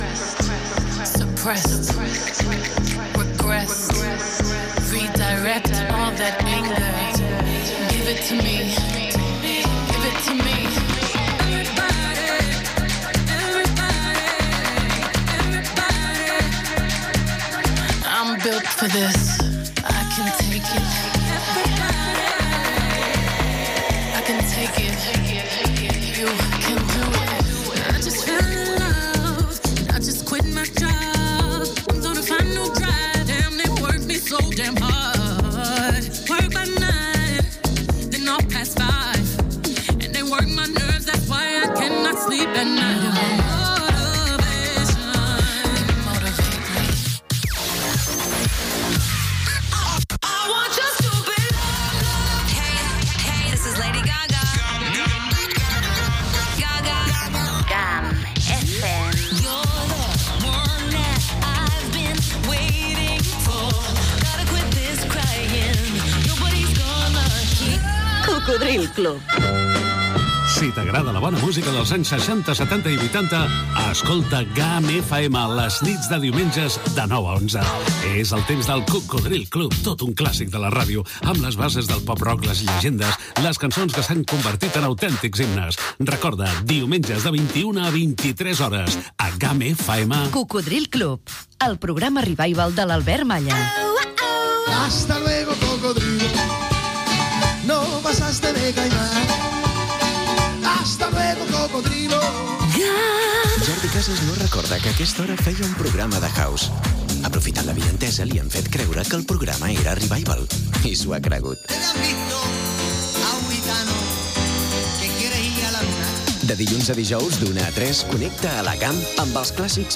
Musical